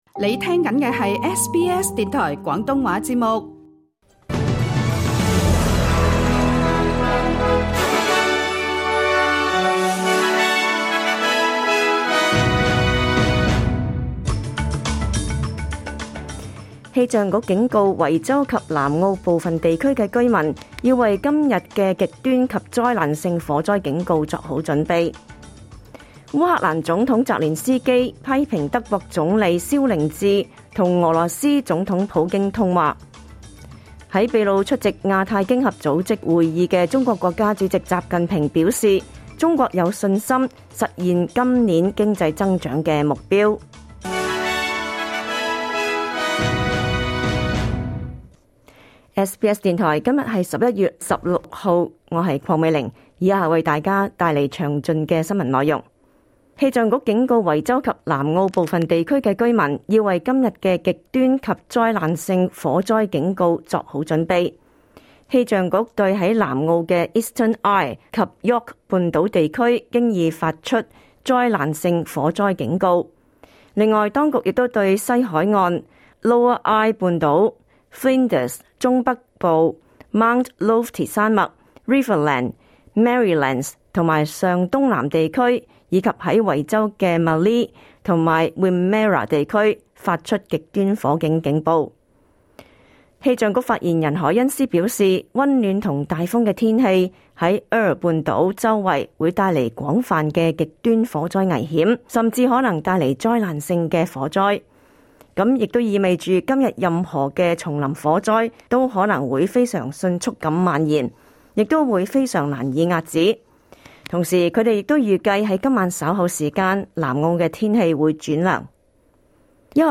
2024 年 11 月 16日 SBS 廣東話節目詳盡早晨新聞報道。